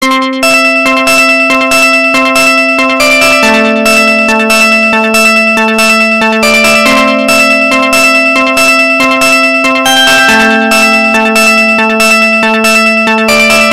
Рингтон Громкая мелодия на звонок телефона